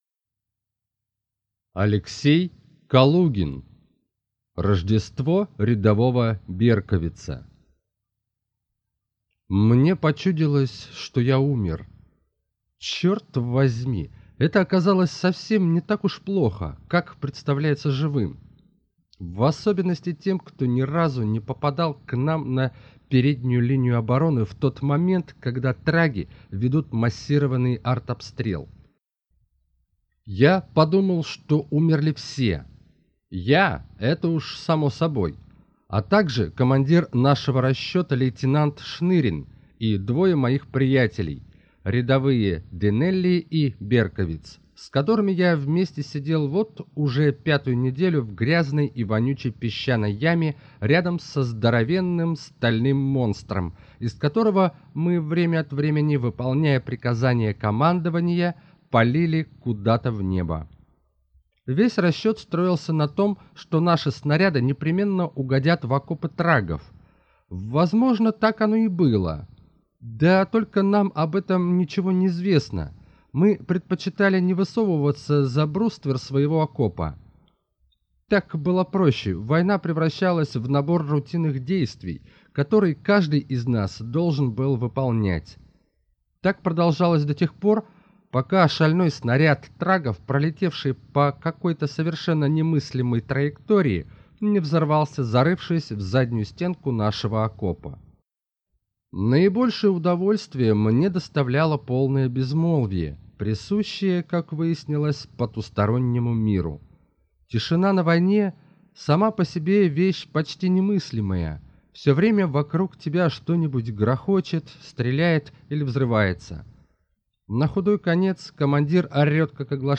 Аудиокнига Рождество рядового Берковица | Библиотека аудиокниг
Прослушать и бесплатно скачать фрагмент аудиокниги